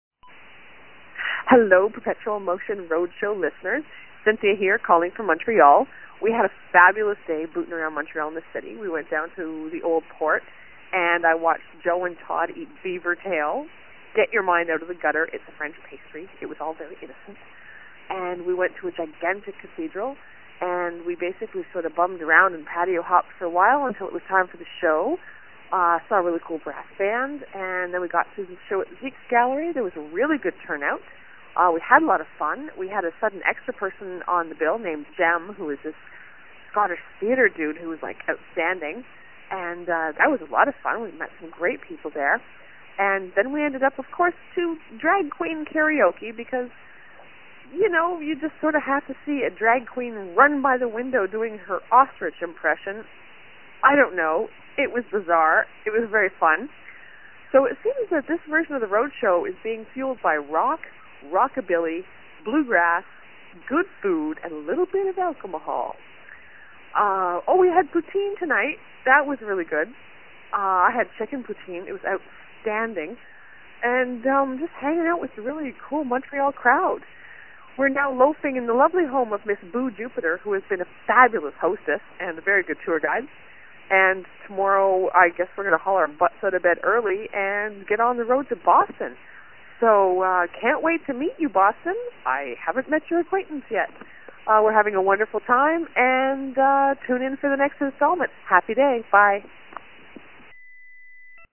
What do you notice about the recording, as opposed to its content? Every day they were on the road, one of them called and left a message about something that happened that day, and they were posted below.